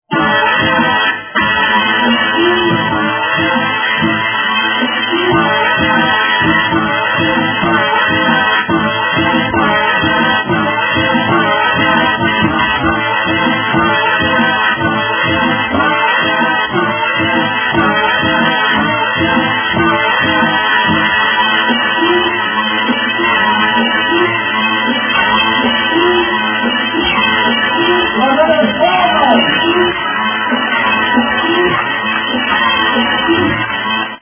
Beat Juggling
beat_juggling.mp3